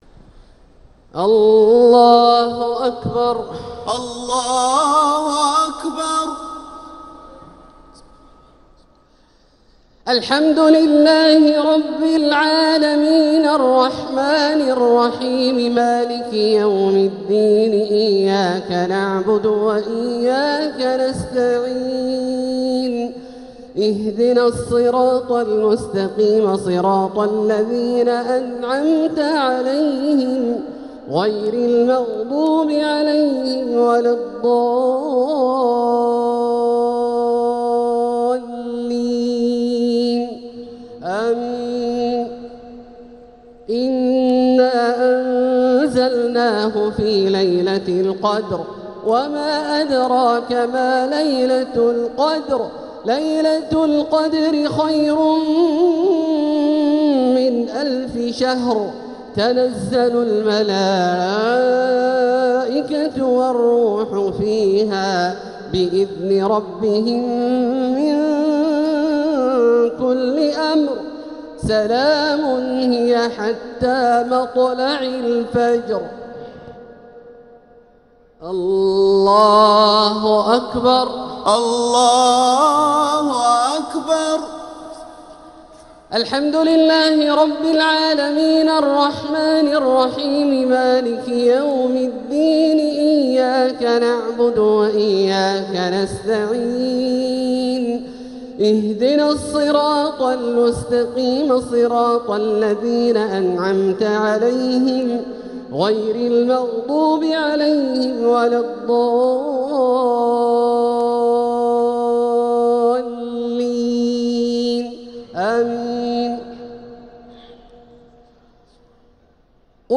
صلاة الشفع و الوتر ليلة 25 رمضان 1446هـ > تراويح 1446هـ > التراويح - تلاوات عبدالله الجهني